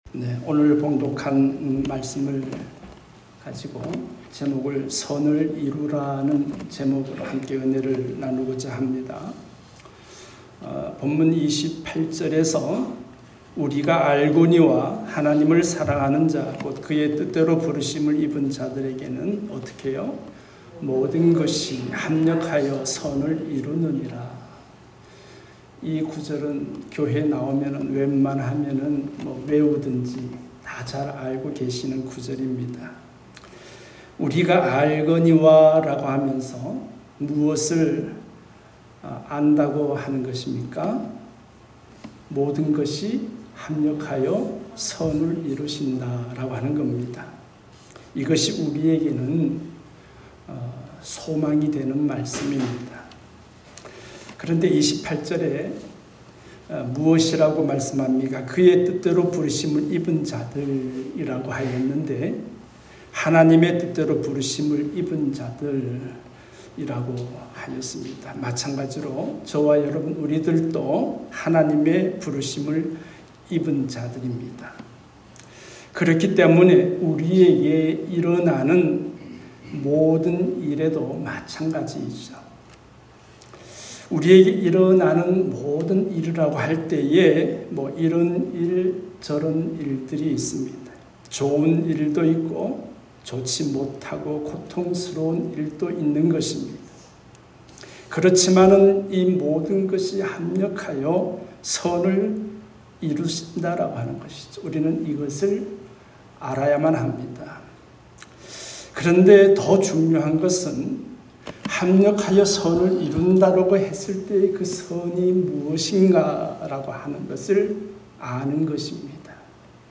선을 이루라 ( 롬8:26-30 ) 말씀